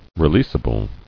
[re·leas·a·ble]